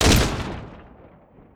PNRoyalMachinegunSound.wav